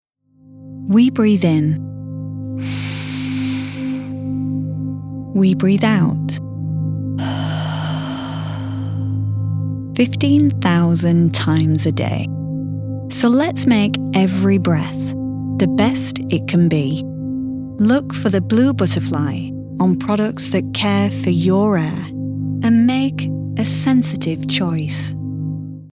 British VO Pro
TV Ad, Sensitive Choice